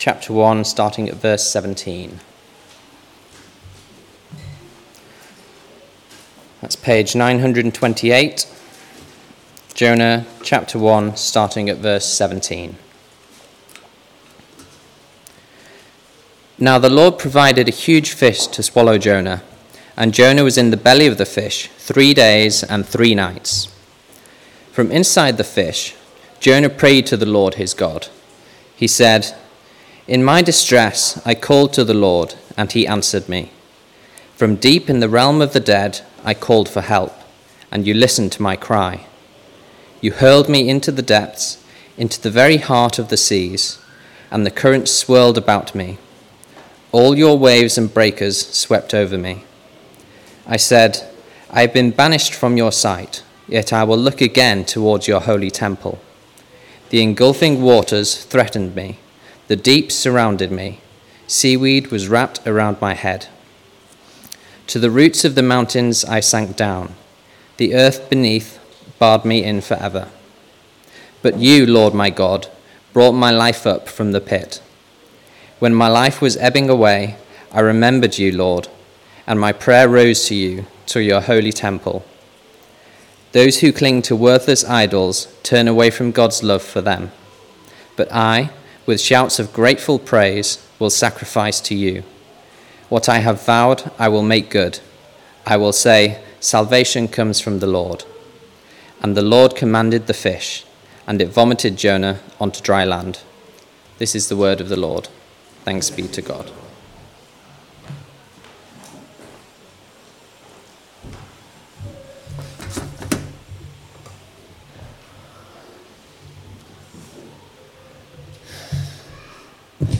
9Jul23-sermon-on-Jonah-2.mp3